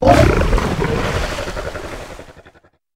dondozo_ambient.ogg